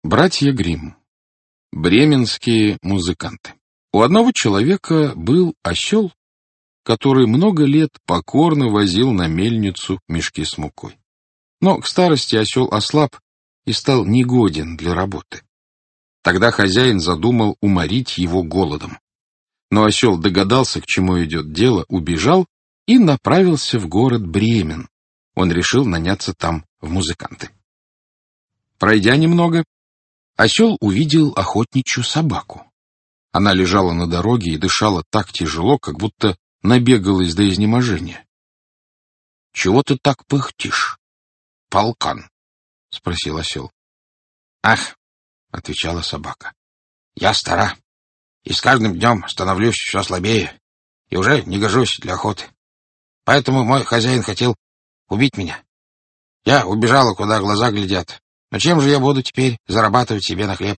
Аудиокнига Сказки | Библиотека аудиокниг